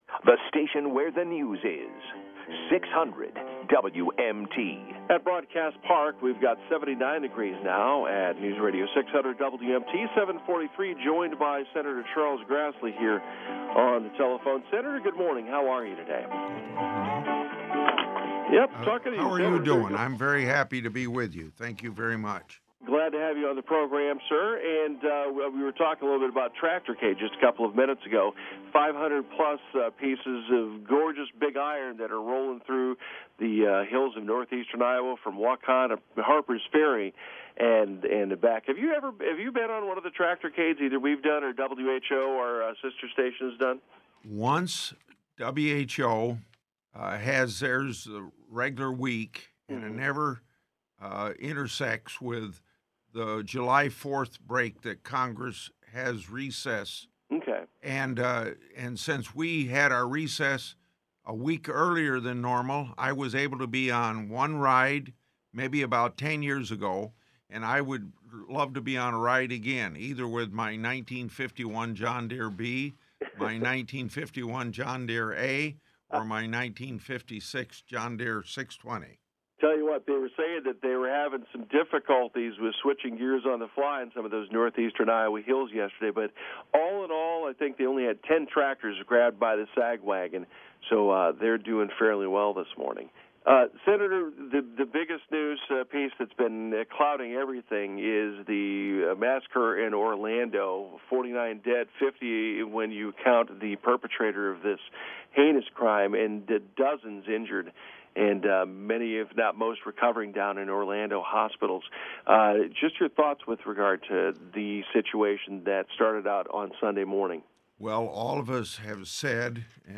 Public Affairs Program, 6-14-16, WMT.mp3